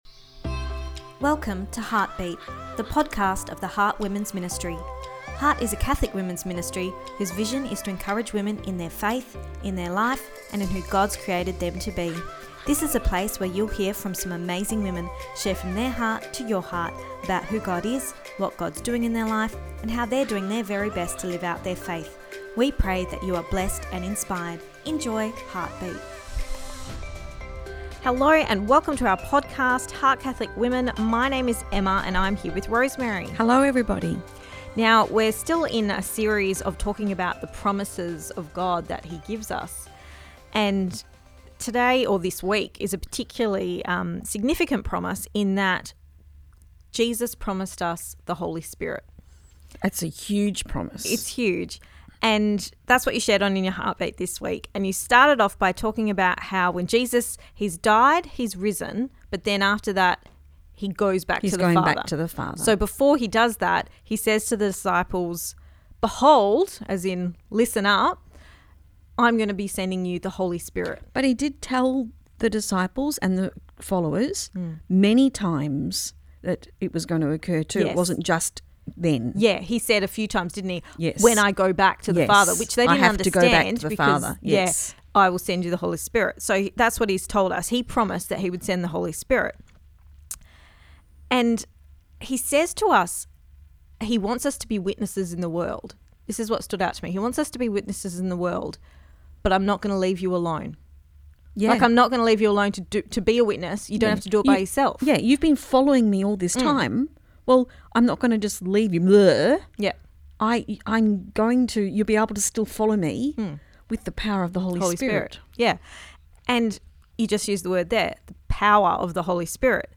Episode 114 – The Promise of the Holy Spirit (Part 2 Our Chat)